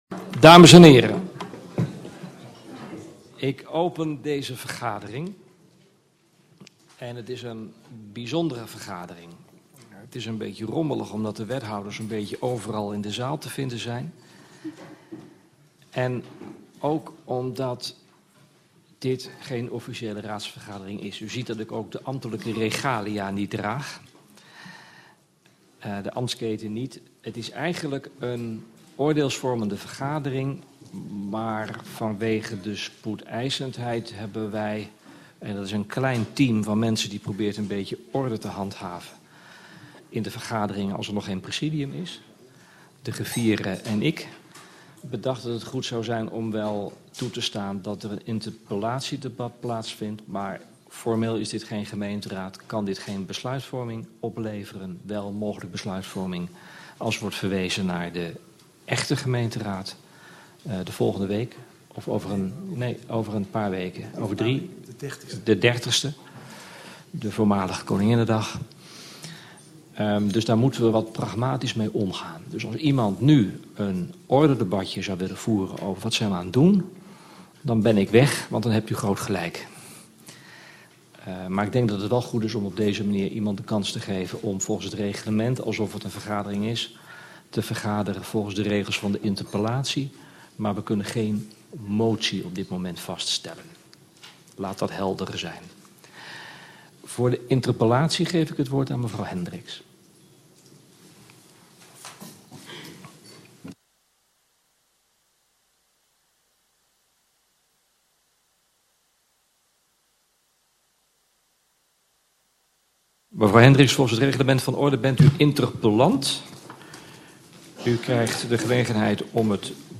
Oordeelsvormende raadsvergadering 09 april 2014 19:00:00, Gemeente Venlo
WEEK II POLITIEK FORUMDatum 9 april 2014Aanvang 19:00 uurLocatie Stadhuis raadszaal